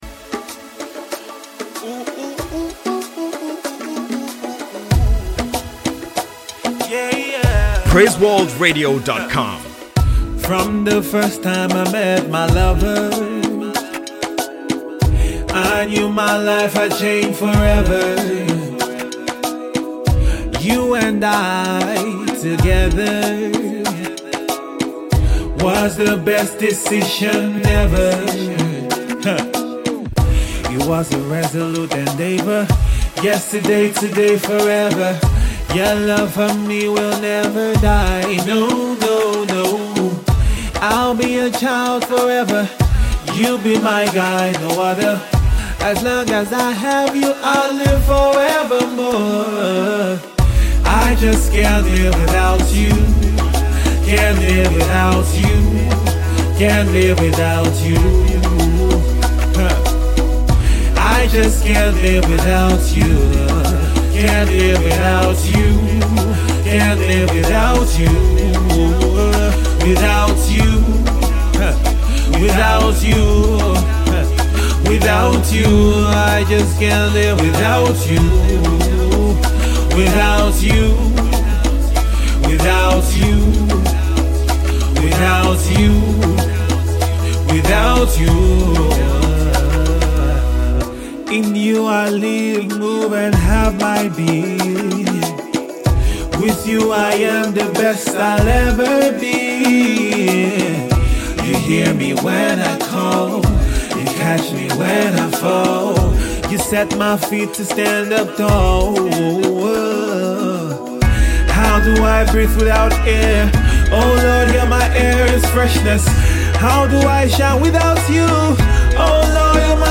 has had his head buried in making quality Gospel music.
smooth, warm and silky voice texture